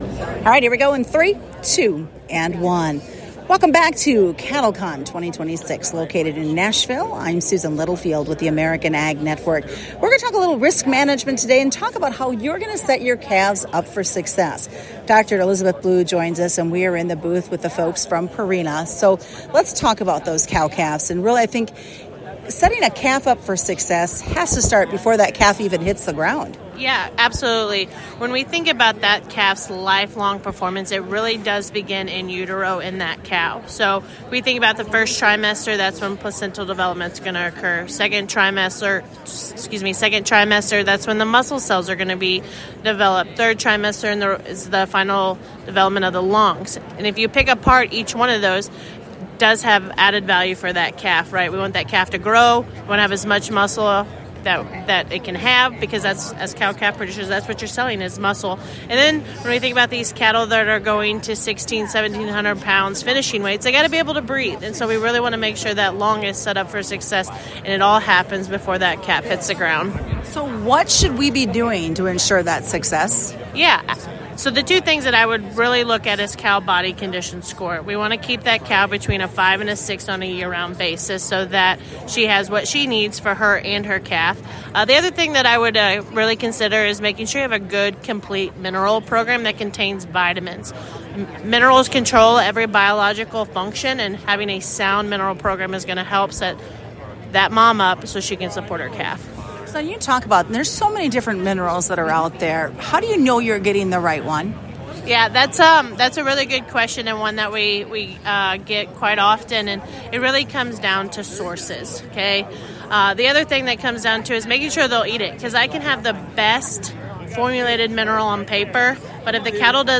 We think of risk management from a grain perspective, now the focus is on the cows and how you can set them up for success and ROI within your cattle herd. During CattleCon I sat down with